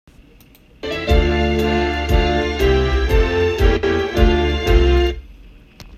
After combining multiple tracks from a CD on a single song to a single track I am getting glitches between the segments.